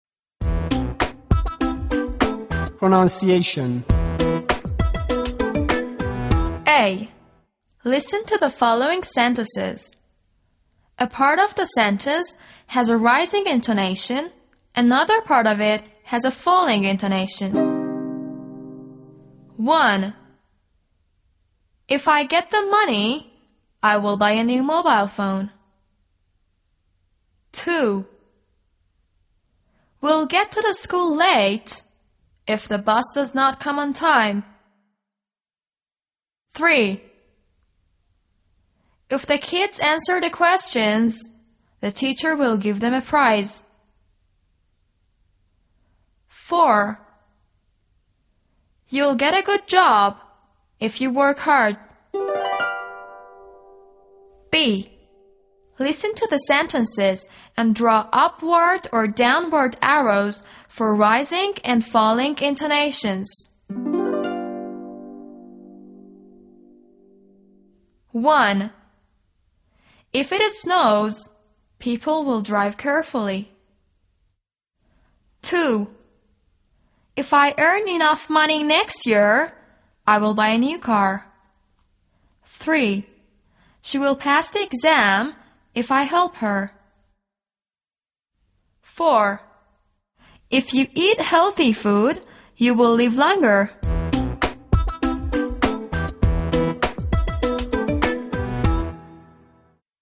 Pronunciation
V.2-L.3-pronunciation.mp3